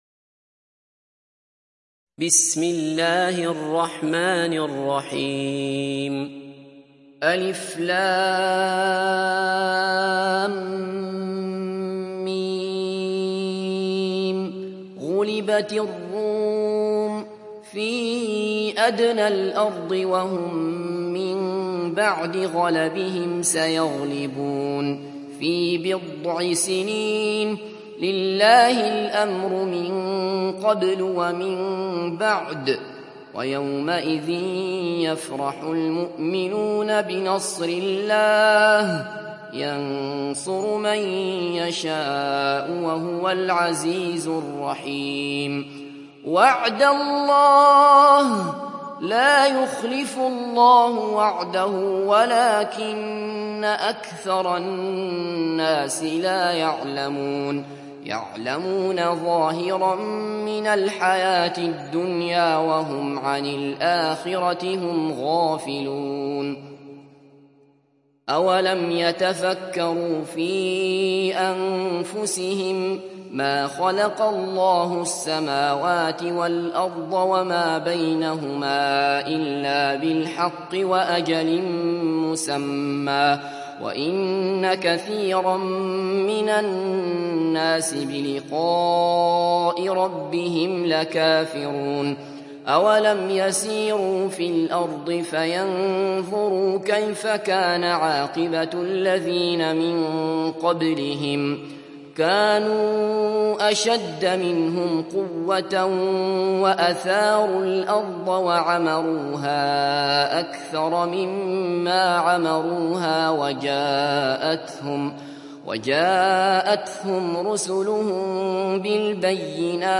Sourate Ar Rum mp3 Télécharger Abdullah Basfar (Riwayat Hafs)